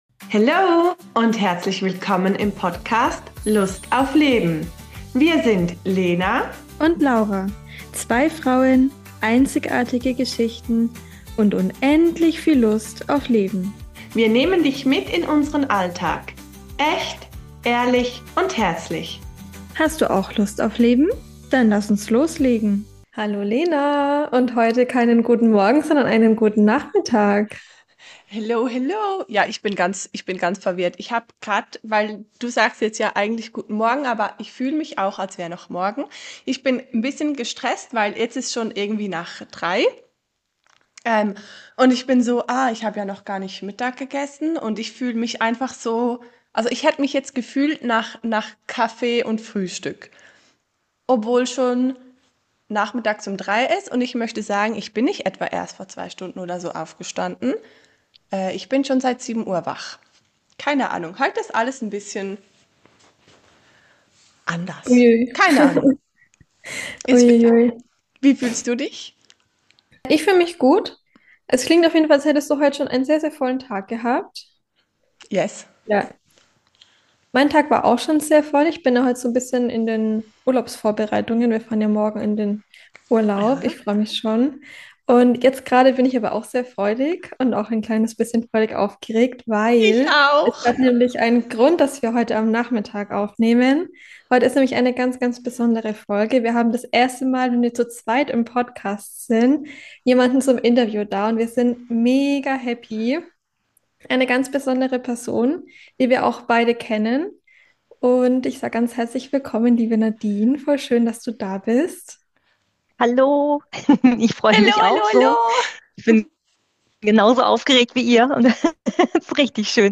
Beschreibung vor 7 Monaten Unsere Podcast-Premiere zu dritt!